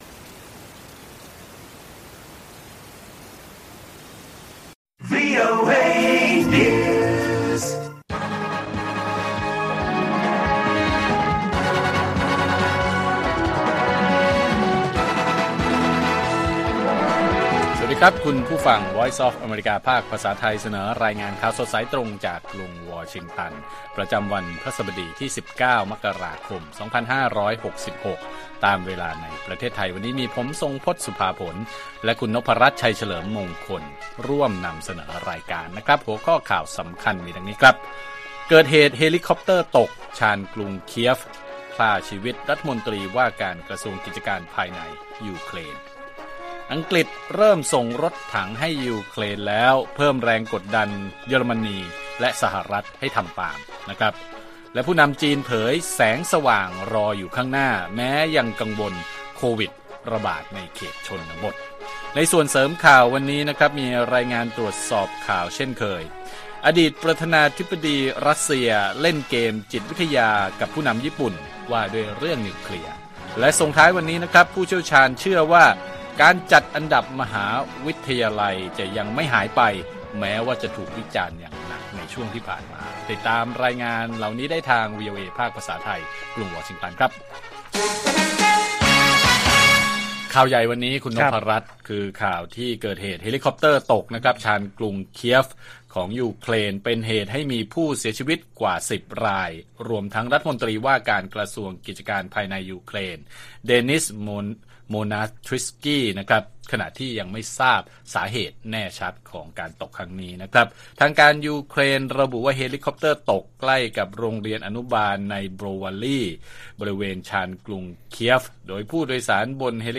ข่าวสดสายตรงจากวีโอเอไทย พฤหัสบดี ที่ 19 ม.ค. 66